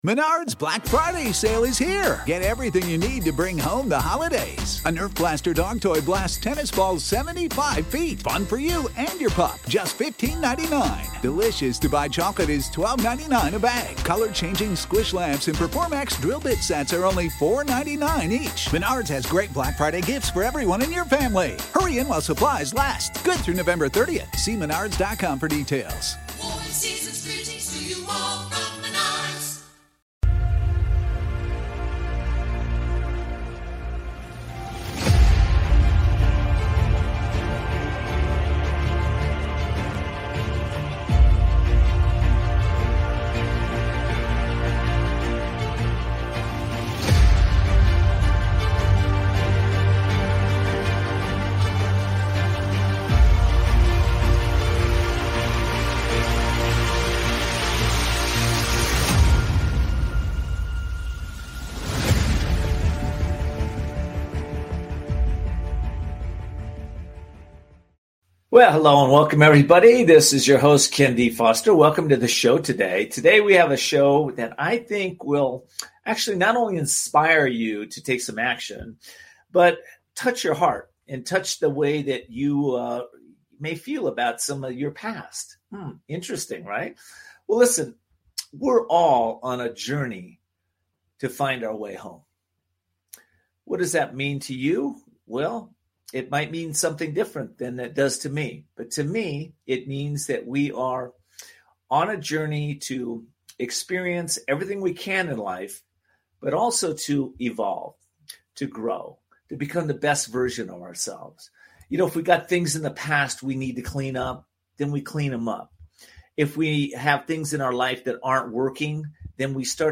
Voices of Courage is a power packed radio, podcast and TV show, where audiences engage to grow their business and transform their life’s. The 60-minute weekly shows are provocative, entertaining and transformational.
UK Health Radio Podcast